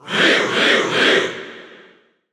Ryu_Cheer_NTSC_SSB4.ogg.mp3